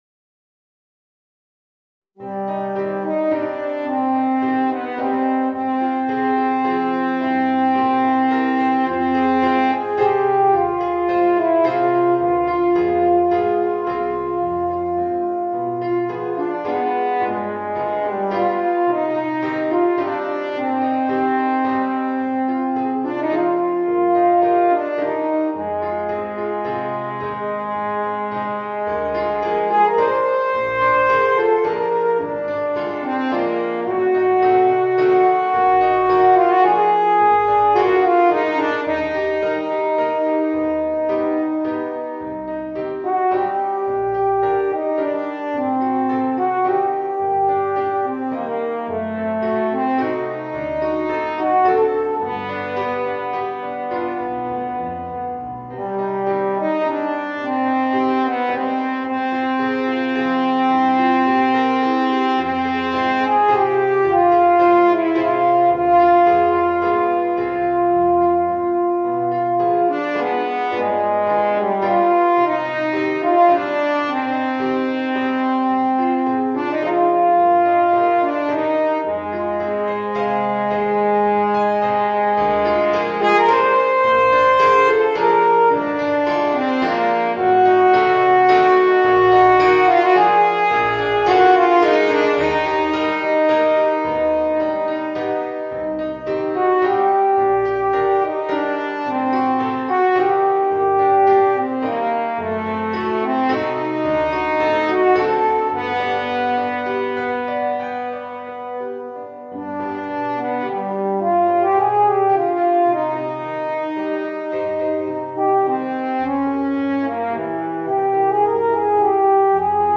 Voicing: Horn and Piano